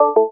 klong.wav